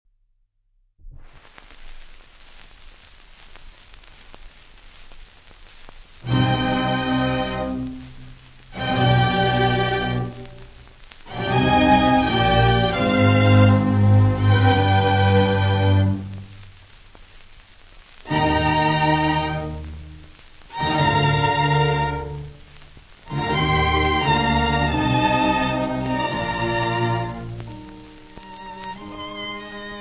• Orchestra e Coro Angelicum [interprete]
• registrazione sonora di musica